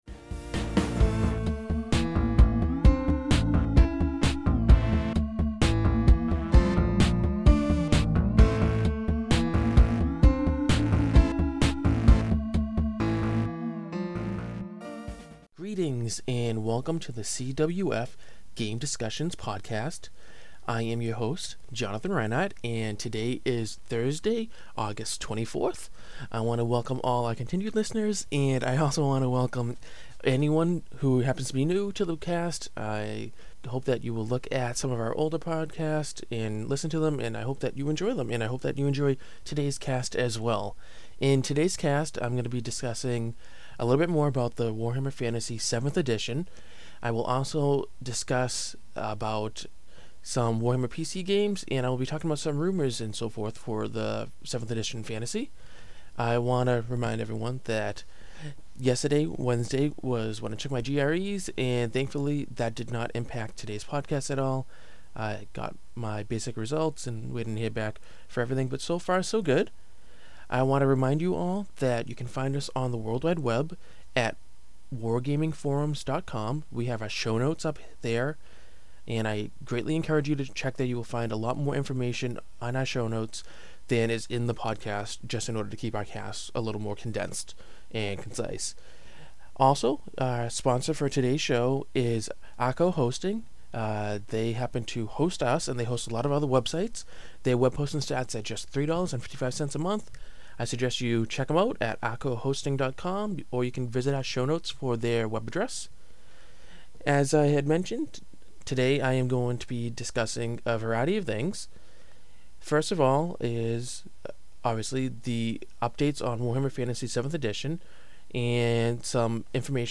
CWF-2006-08-24 Solo-cast w/More Warhammer Fantasy Rumors for 7th edition | Wargaming Recon